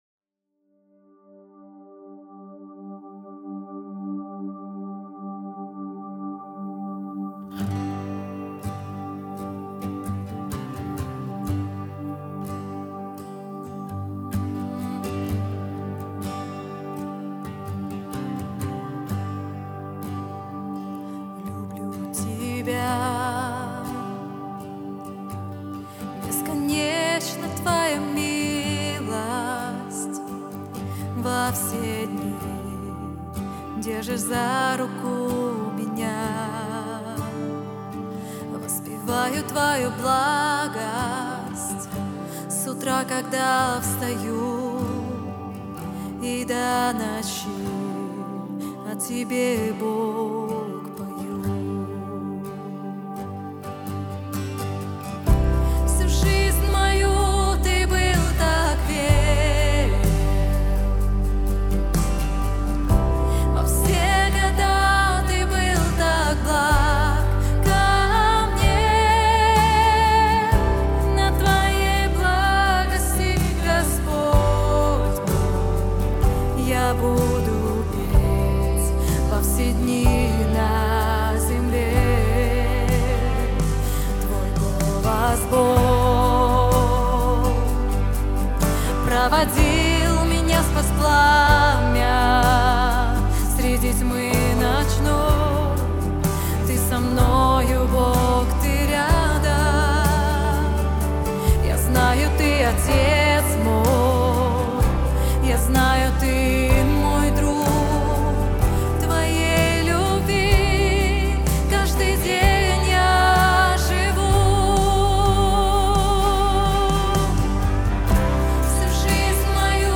153 просмотра 299 прослушиваний 17 скачиваний BPM: 125